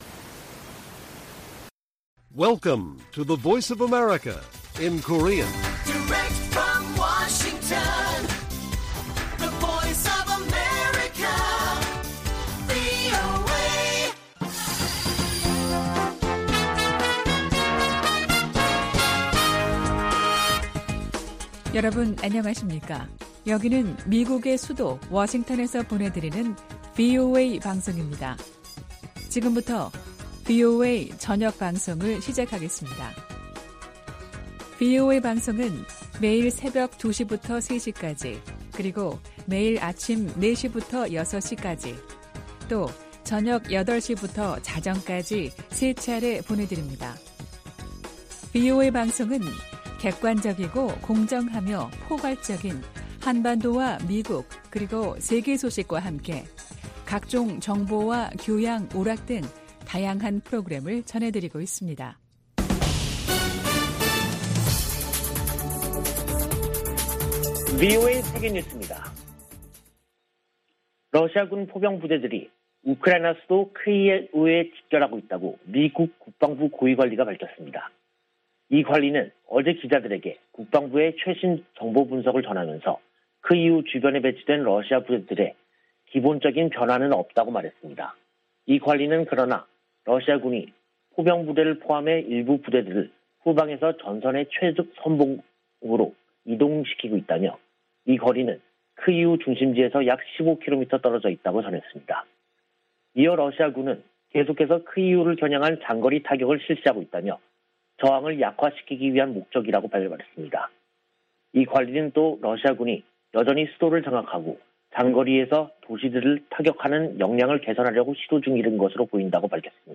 VOA 한국어 간판 뉴스 프로그램 '뉴스 투데이', 2022년 3월 18일 1부 방송입니다. 백악관은 북한의 미사일 발사를 거듭 규탄하고 한일 양국 방어 의지를 재확인했습니다. 한국의 윤석열 차기 정부가 현 정부보다 미국의 정책에 더 부합하는 외교정책을 추구할 것으로 미 의회조사국이 분석했습니다.